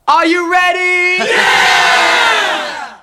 areyouready.wav